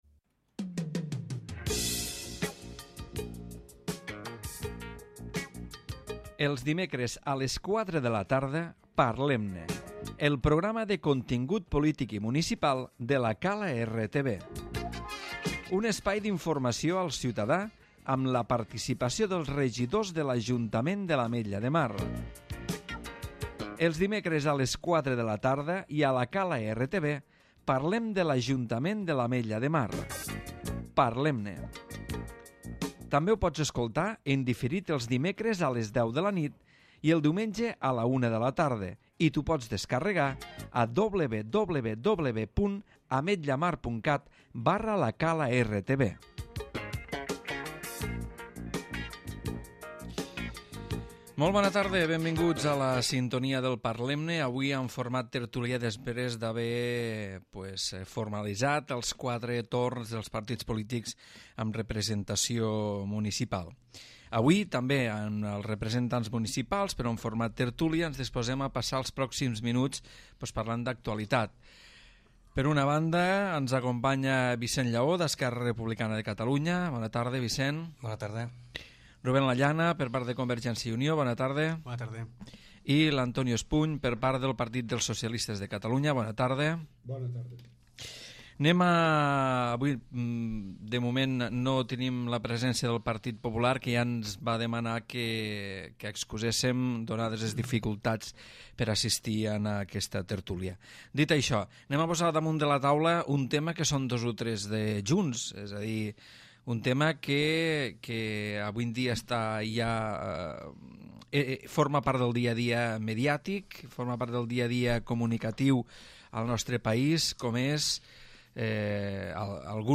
Parlem-ne en el seu format tertúlia. Programa de contingut polític per on passen els representants dels grups municipals amb representació al Ple. Avui han participat, Vicent Llaó(ERC), Ruben Lallana(CiU) i Antonio Espuny(PSC), amb el rerafons de les eleccions del 25 de novembre i la indepndència de Catalunya.